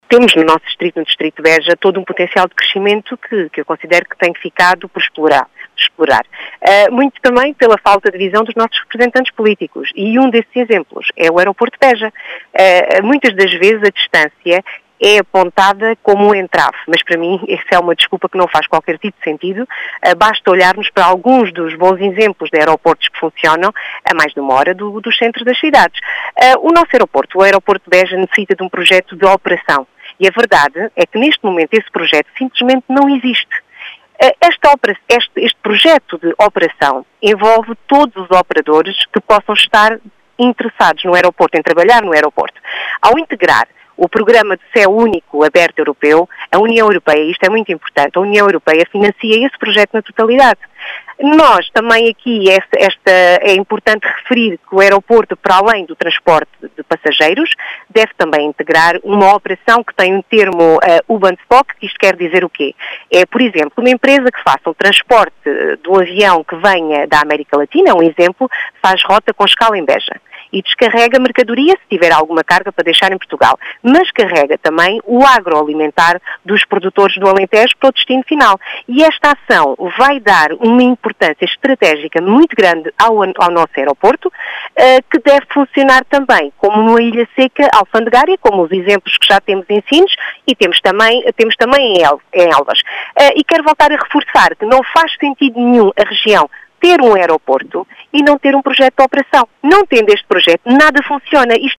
Explicações que foram deixadas na Rádio Vidigueira